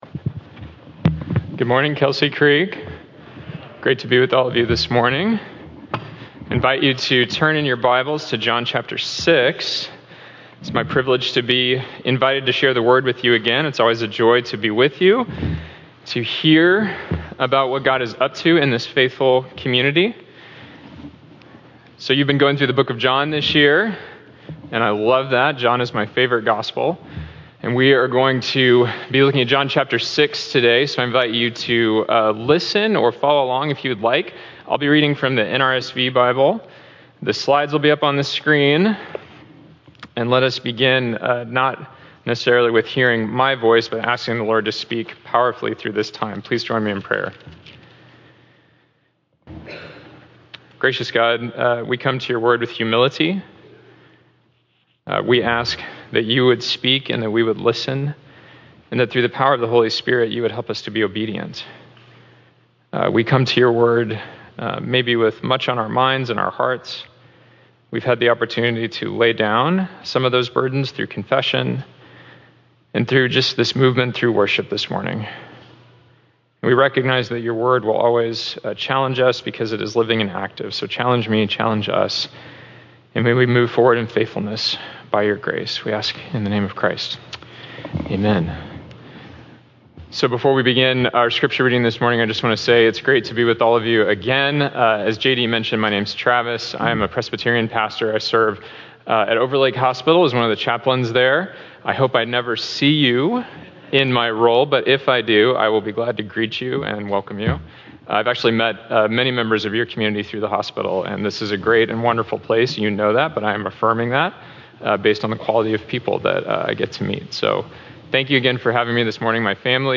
We will build upon last’s week sermon where we saw Jesus feed the 5,000 with physical bread. Now we will learn how Jesus is our spiritual bread and source of salvation if we believe & follow Him.